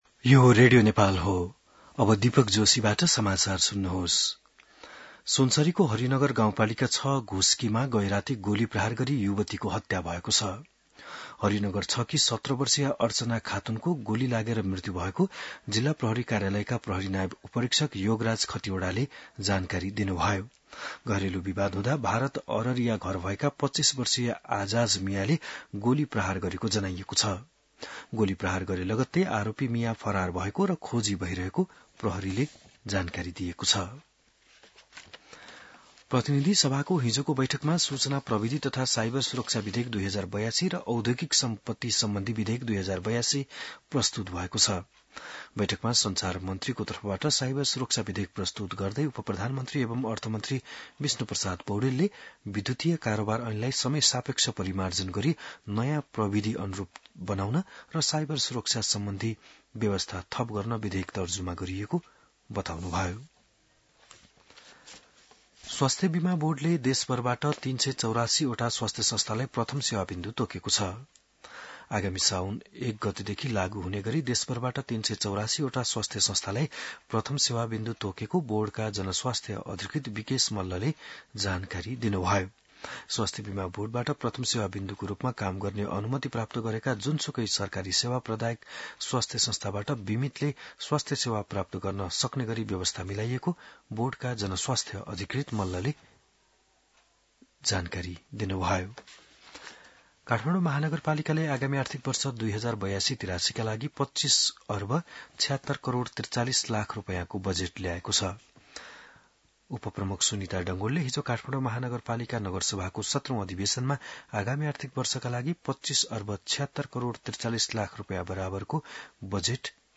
बिहान १० बजेको नेपाली समाचार : ३० असार , २०८२